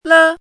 chinese-voice - 汉字语音库
le1.mp3